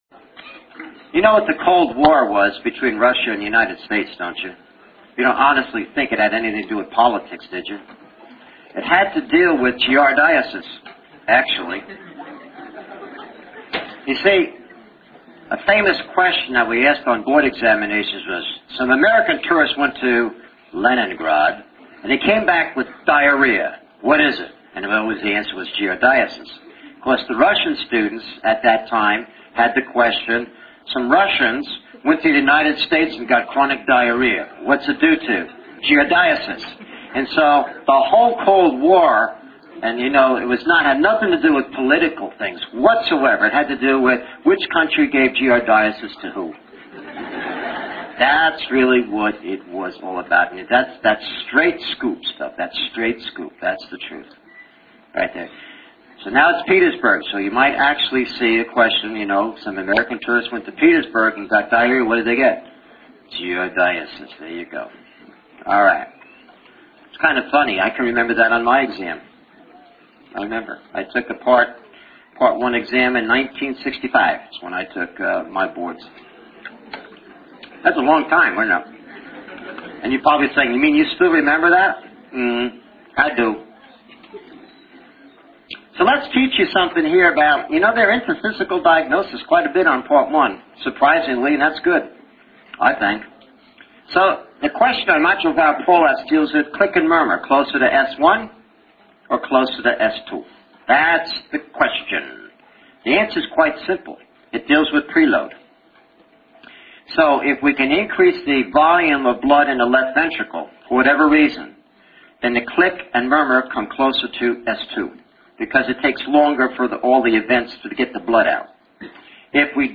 Audio Lectures